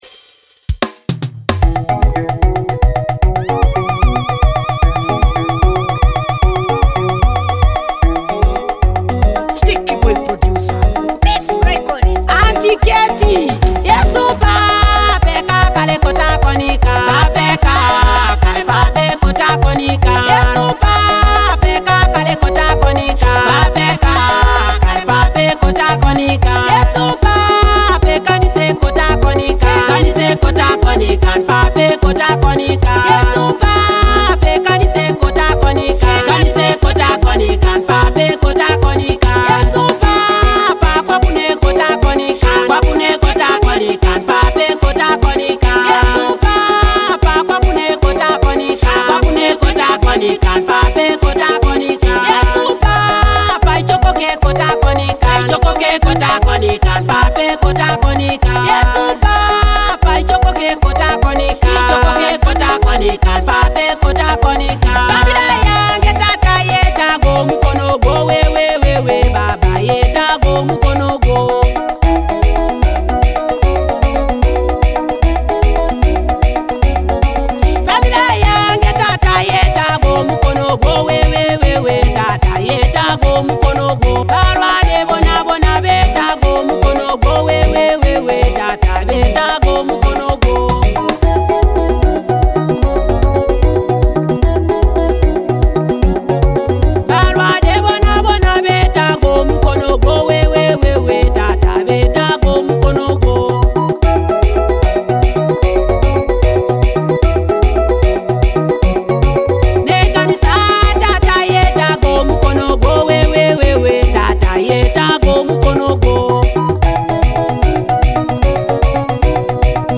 an MP3 track perfect for anyone seeking a gospel praise song
powerful gospel music